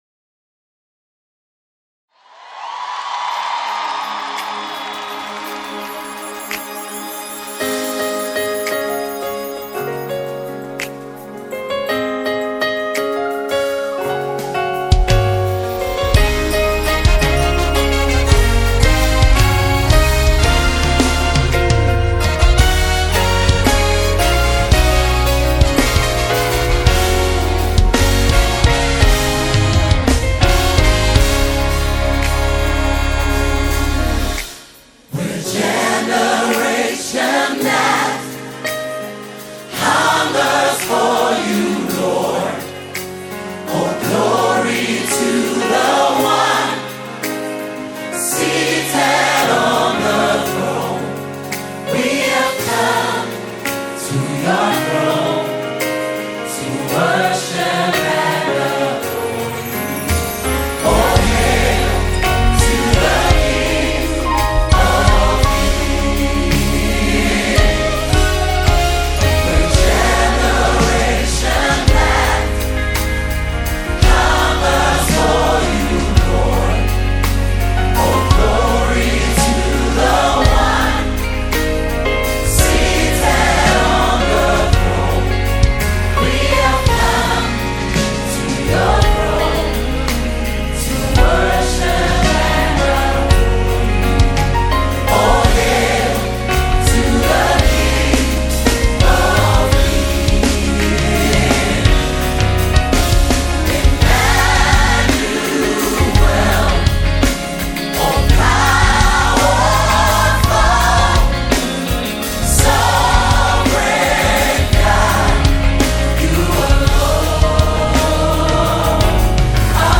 a powerful worship song to uplift and inspire you.